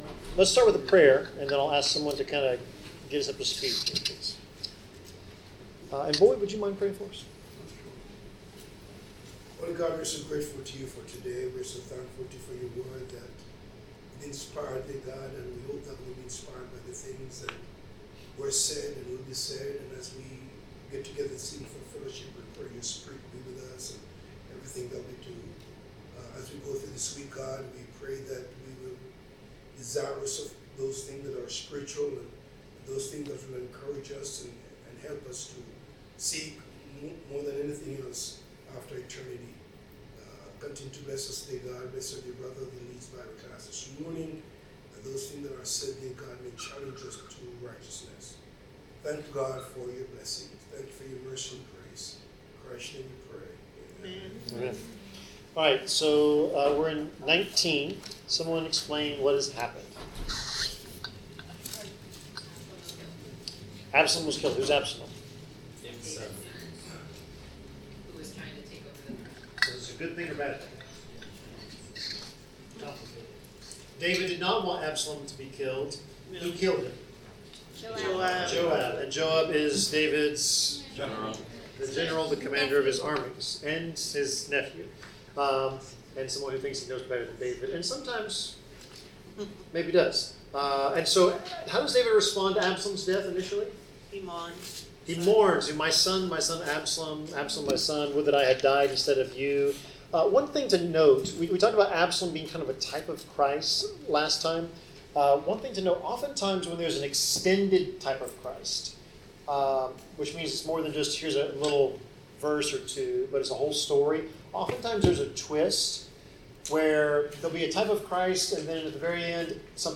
Bible class: 2 Samuel 19-21 (Conflict in the Kingdom)
Service Type: Bible Class